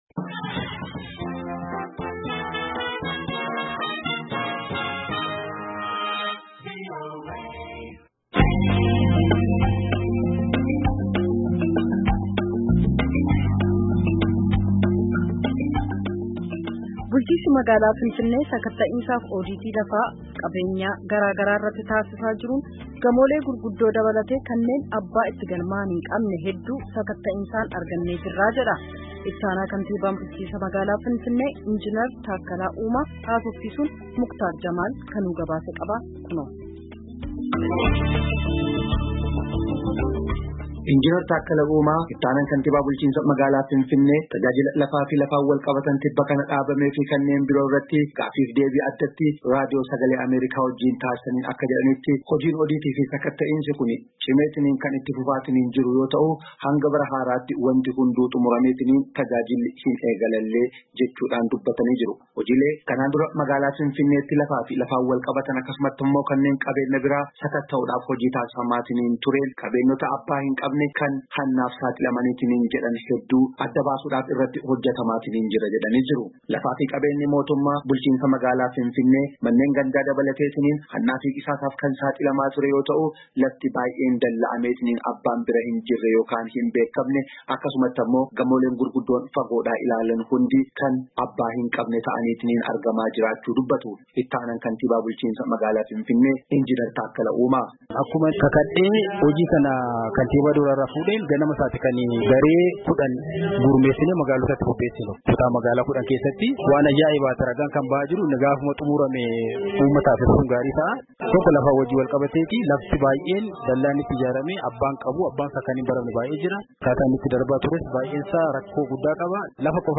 Takkalaa Uumaa, Itti aanaan kantibaa bulchinnsa magaalaa Finffinee